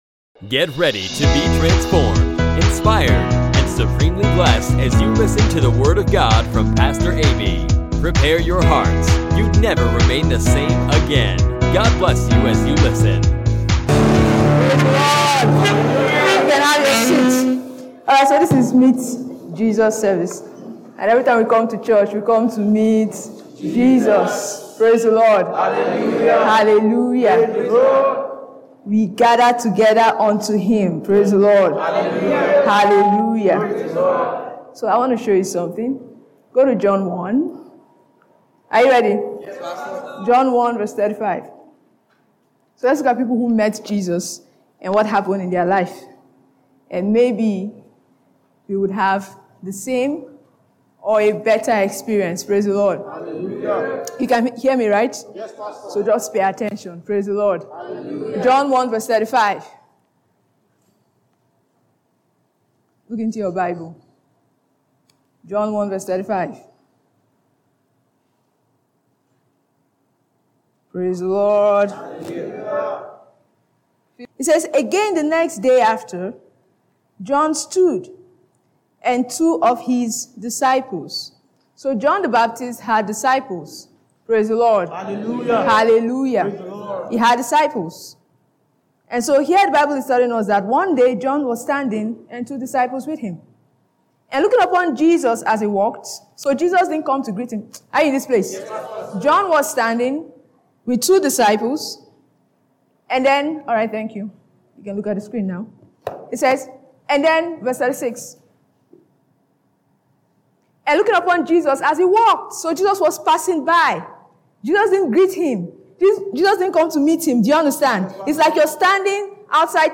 Jesus said any one who comes to him never hunger and anyone who believes in him will never thirst! He assures satisfaction in his presence, find out and bask in the glory of the word as you listen to this special message by Pastor.